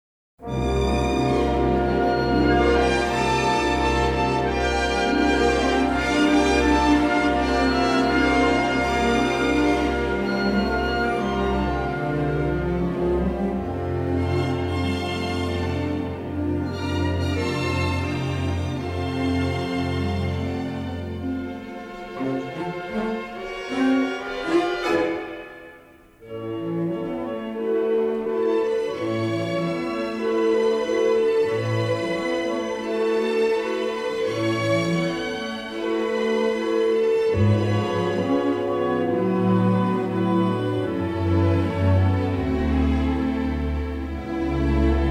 and he responded with a gorgeous, symphonic score.
stereo LP configurations